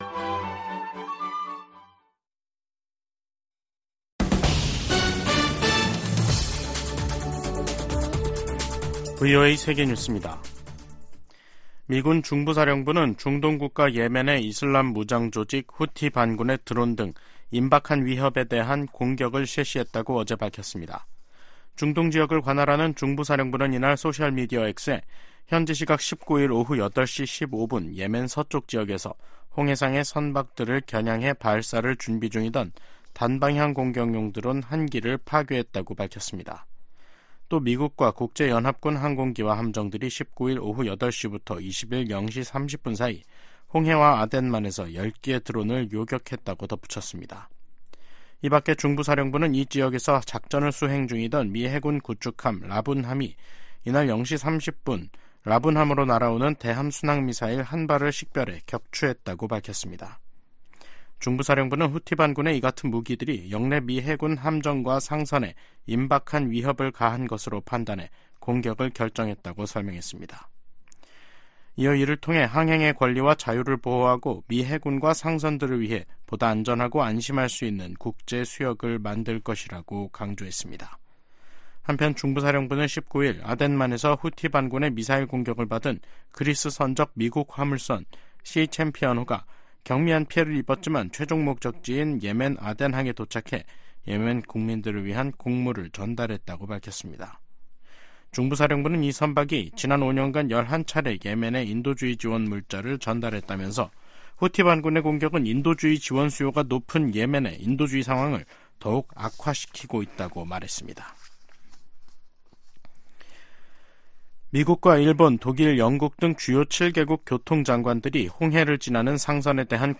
VOA 한국어 간판 뉴스 프로그램 '뉴스 투데이', 2024년 2월 21일 2부 방송입니다. 러시아가 우크라이나 공격에 추가로 북한 미사일을 사용할 것으로 예상한다고 백악관이 밝혔습니다. 미국 정부는 북일 정상회담 가능성에 역내 안정에 기여한다면 환영할 일이라고 밝혔습니다.